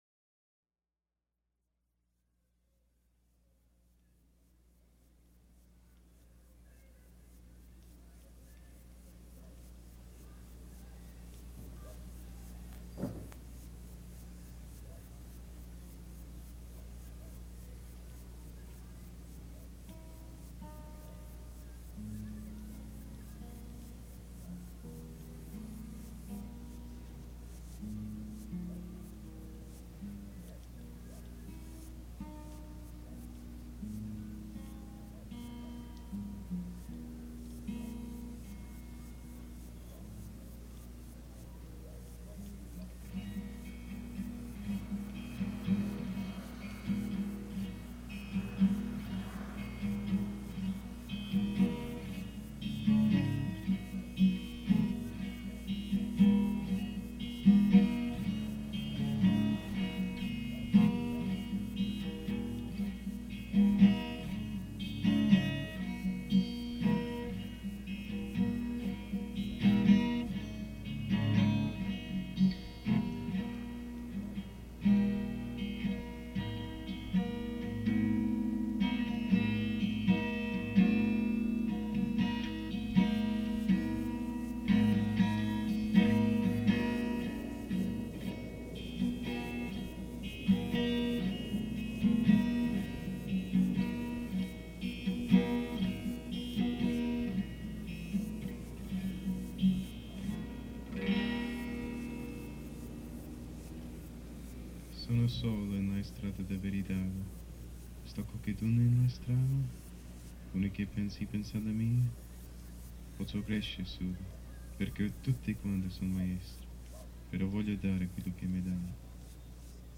poetic, instrumental tracks